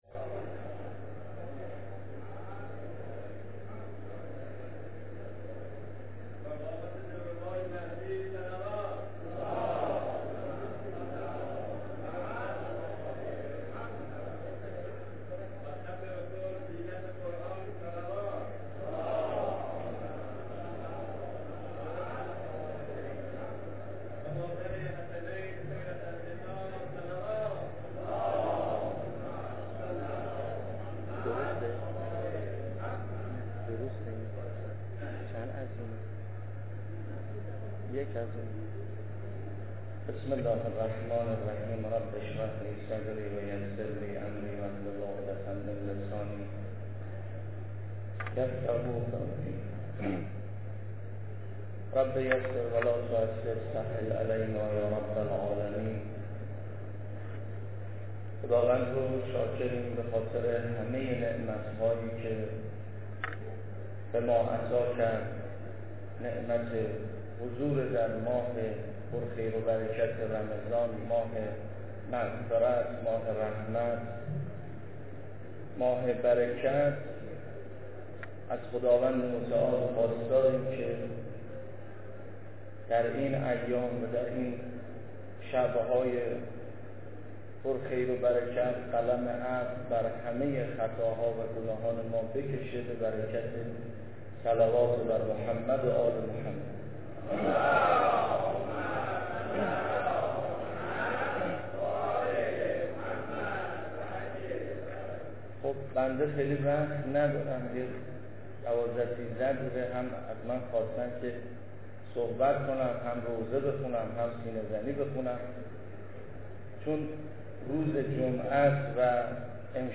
سخنرانی پیش از خطبه